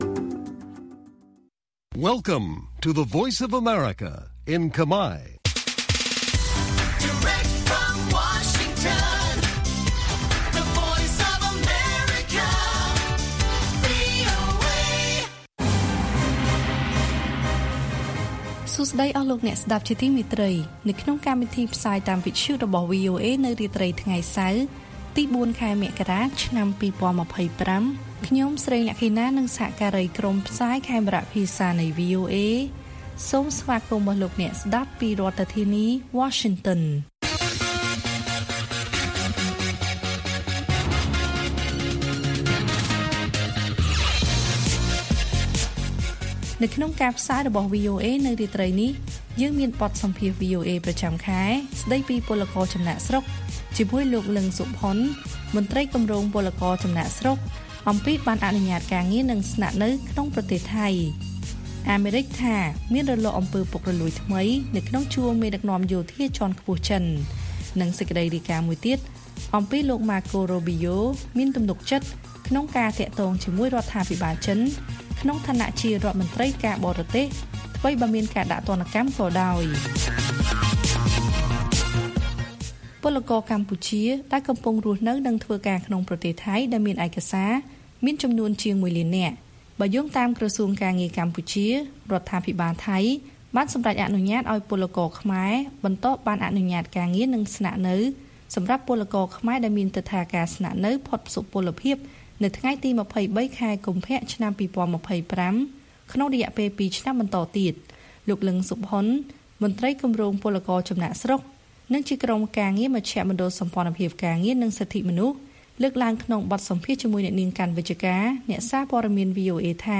ព័ត៌មាននៅថ្ងៃនេះមានដូចជា បទសម្ភាសន៍ VOA ប្រចាំខែស្តីពី«ពលករចំណាកស្រុក» អំពីបណ្ណអនុញ្ញាតការងារនិងស្នាក់នៅក្នុងប្រទេសថៃ។ អាមេរិកថាមានរលកអំពើពុករលួយថ្មីក្នុងជួរមេដឹកនាំយោធាជាន់ខ្ពស់ចិន និងព័ត៌មានផ្សេងទៀត៕